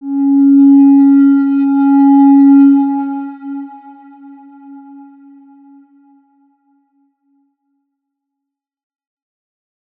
X_Windwistle-C#3-pp.wav